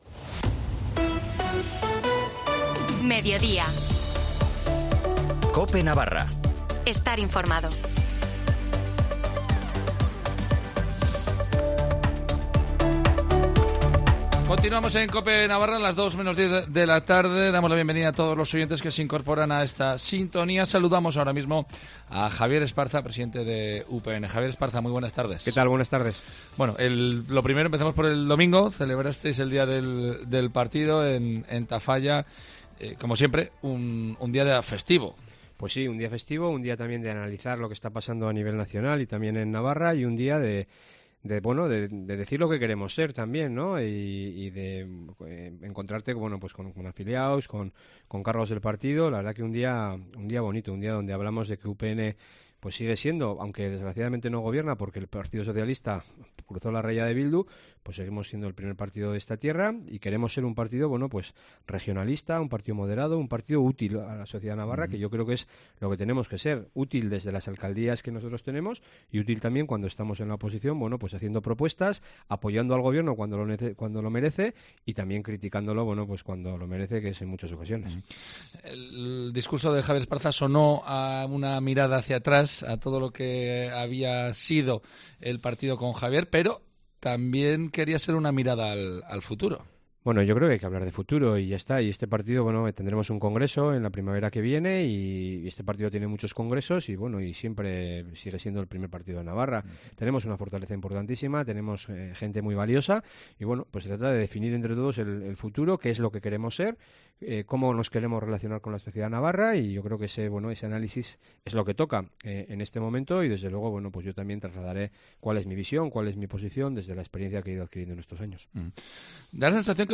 Entrevista con javier Esparza (presidente de UPN)